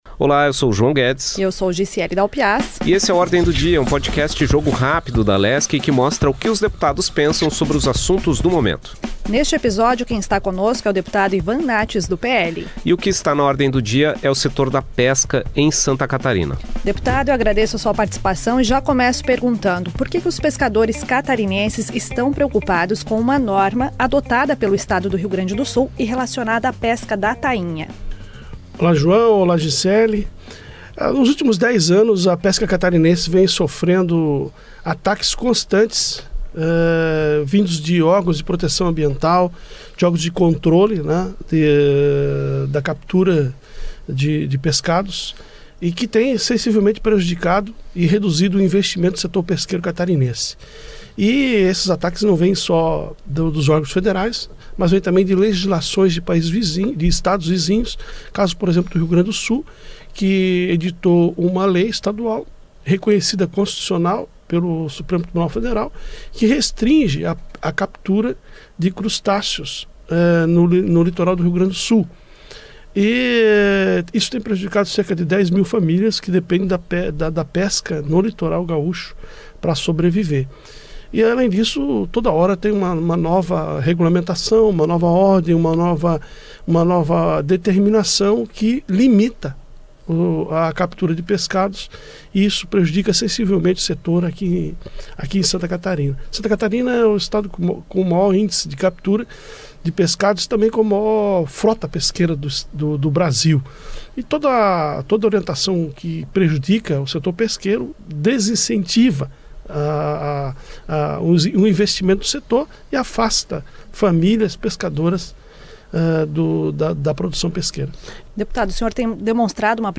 O videocast/podcast da Rádio AL, traz, nesta semana, uma conversa com o deputado Ivan Naatz (PL) sobre o assunto.